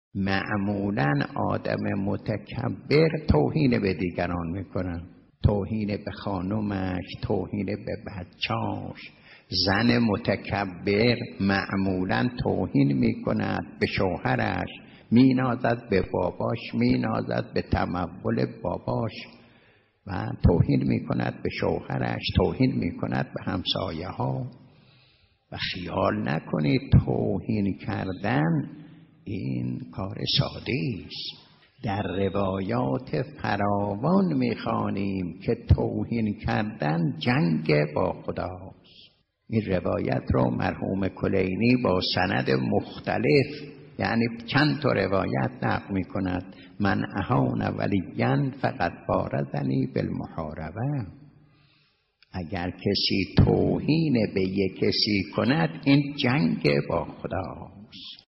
آیت‌الله حسین مظاهری، در درس اخلاق خود توهین و اهانت به مردم را ناشی از تکبر و خود بزرگ‌بینی برشمرده و گفت: اهانت به مردم، جنگ با خداست.